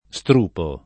strupo [ S tr 2 po ]